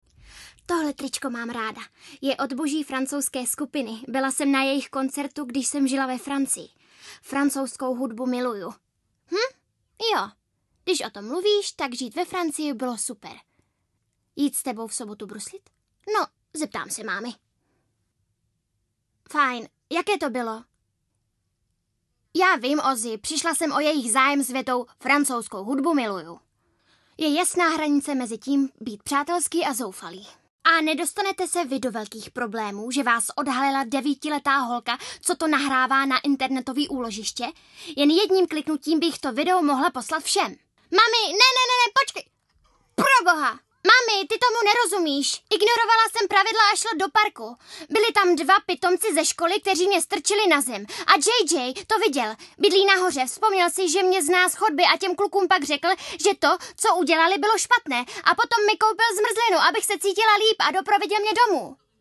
ukázka reklama:
ukázka audio kniha:
ukázka dabing: